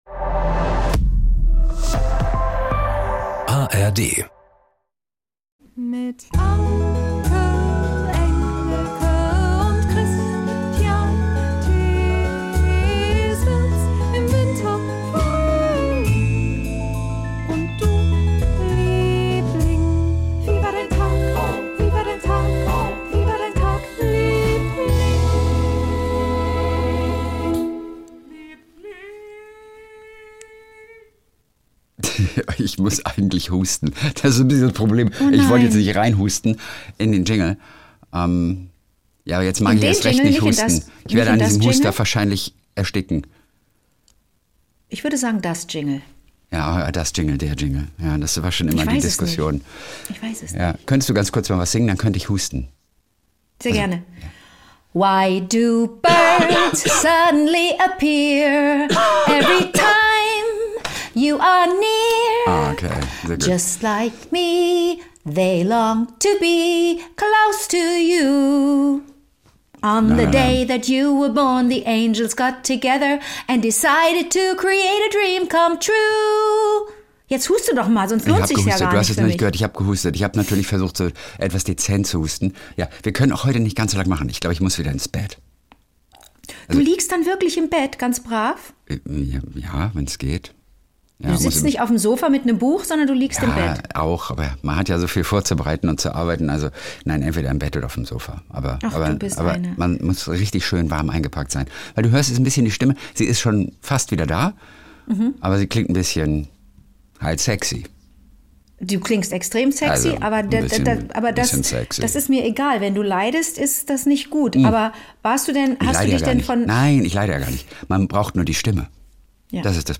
1 KW 46 (Do) Sturm ist erst, wenn die Schafe keine Locken mehr haben (Hörererektionen) 39:32 Play Pause 5h ago 39:32 Play Pause 稍后播放 稍后播放 列表 喜欢 喜欢 39:32 Jeden Montag und Donnerstag Kult: SWR3-Moderator Kristian Thees ruft seine beste Freundin Anke Engelke an und die beiden erzählen sich gegenseitig ihre kleinen Geschichtchen des Tages.